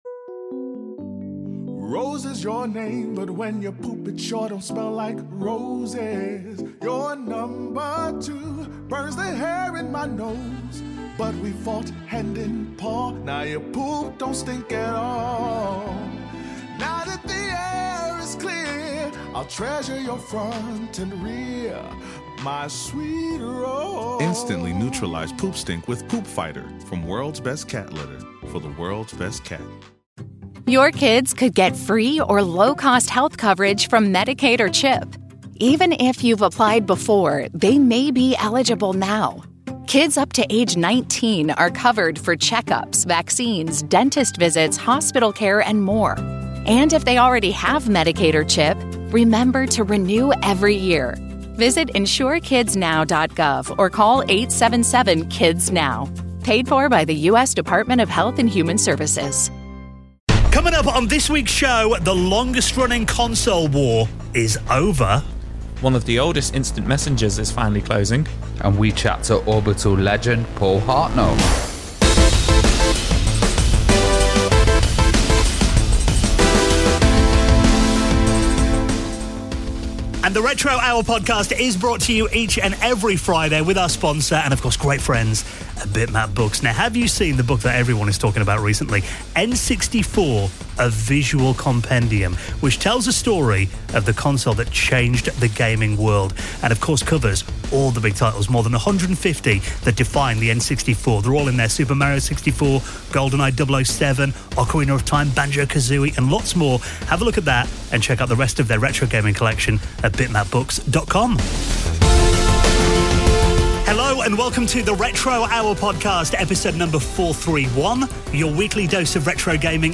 42:48 - Paul Hartnoll Interview